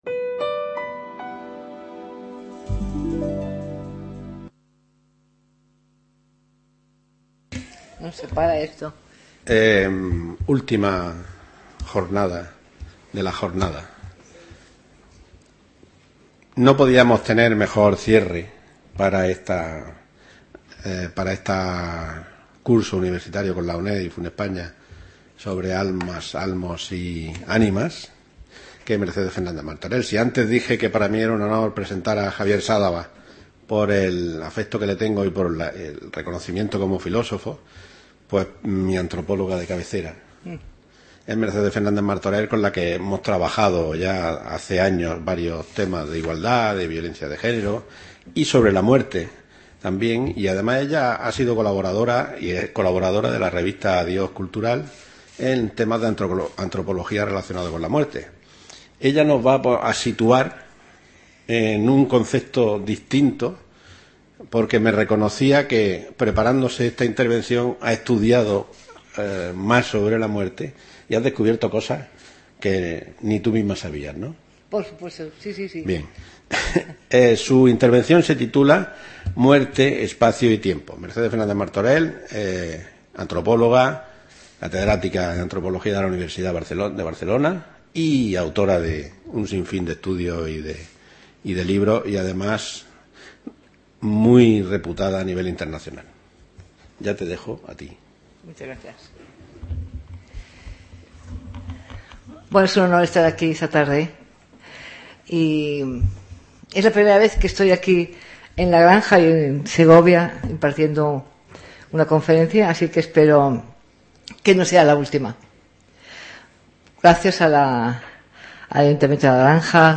debate moderado